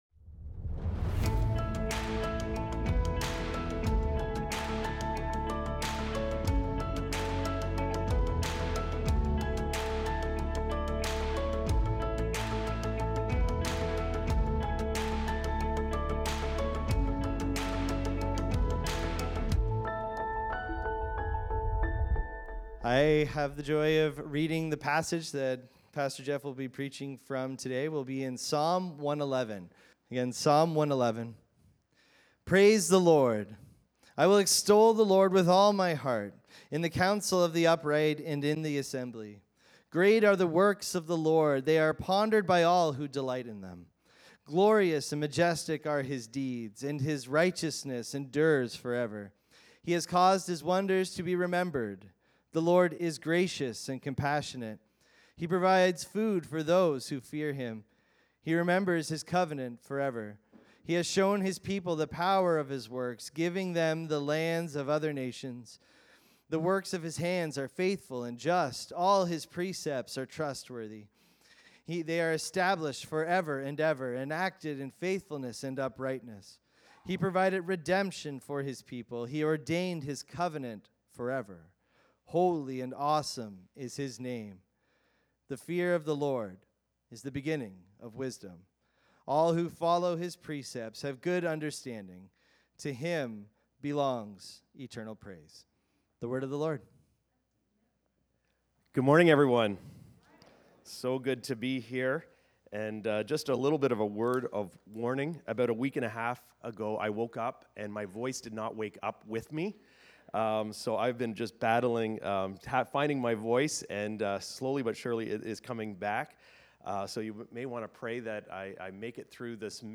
Recorded Sunday, July 13, 2025, at Trentside Fenelon Falls.